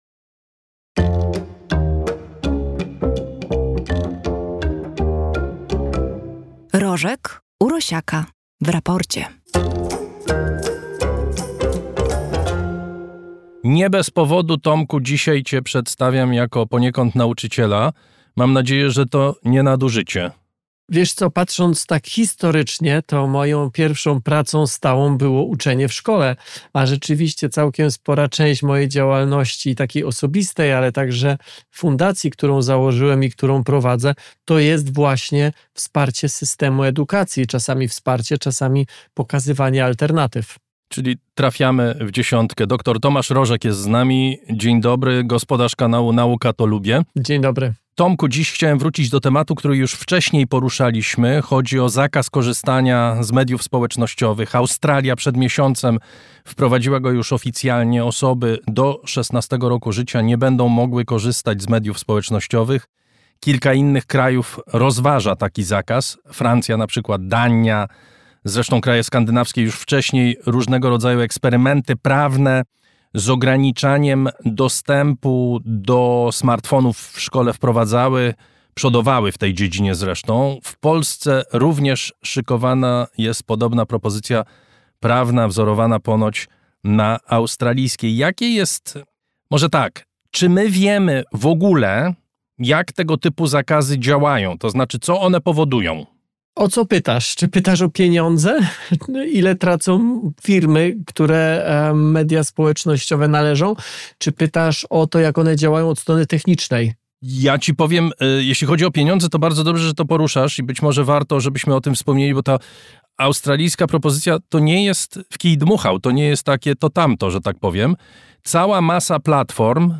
Zapraszamy do posłuchania rozmowy z dr Tomaszem Rożkiem z Nauka. To lubię!